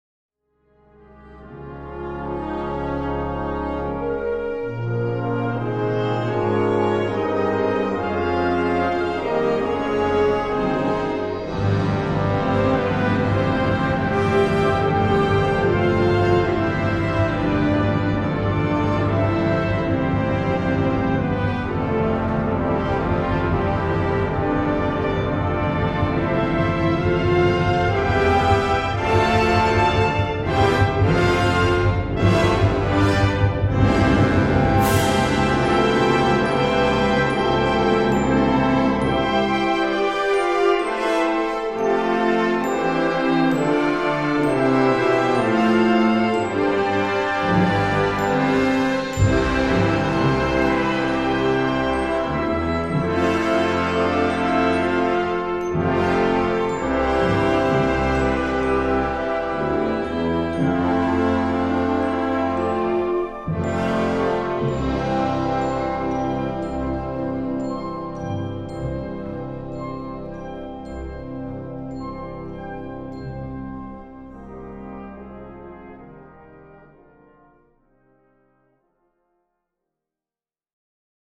Gattung: Ballade
Besetzung: Blasorchester